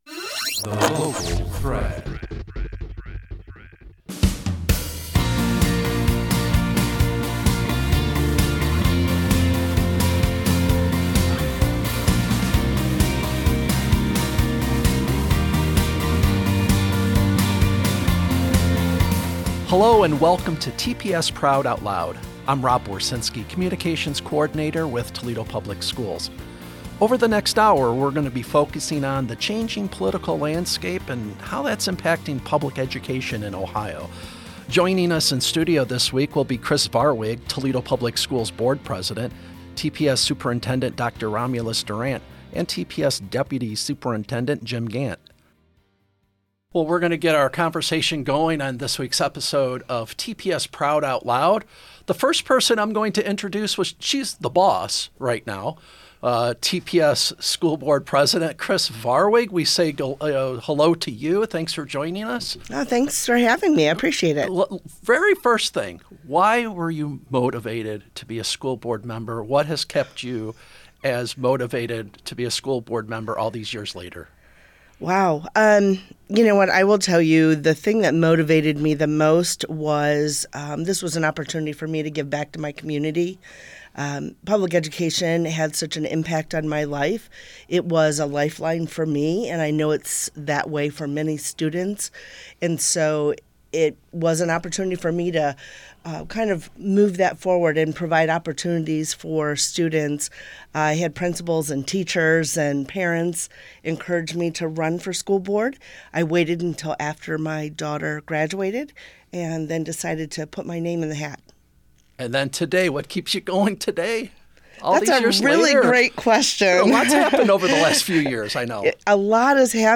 Leaders at Toledo Public Schools sit down for a candid conversation about the current political climate in the State of Ohio and the challenges and opportunities for public education districts.